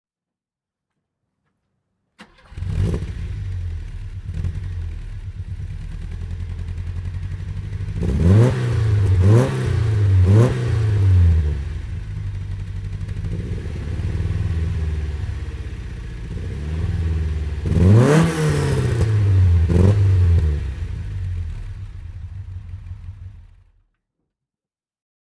Lancia Fulvia 1.3 S Coupé (1974) - Starten und Leerlauf